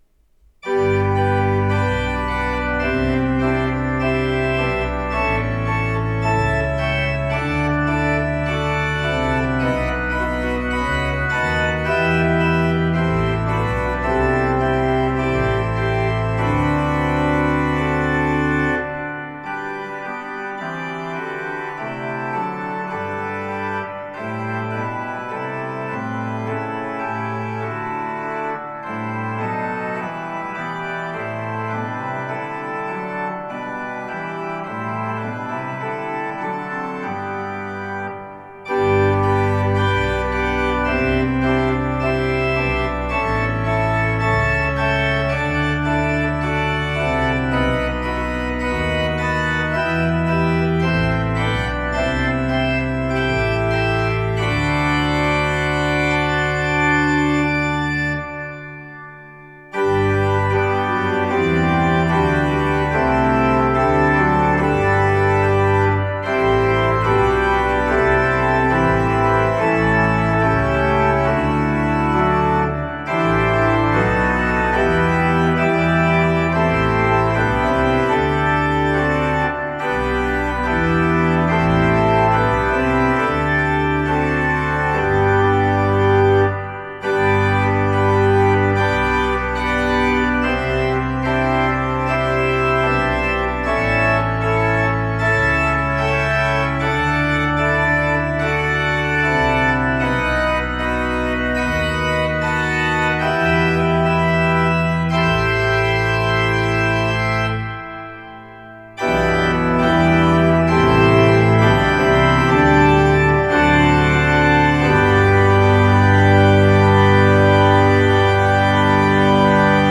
Raise Your Voices To The Lord – The Organ Is Praise
The postludes I play here need to match the spirit of the meeting.
This piece reflects the joy of the occasion.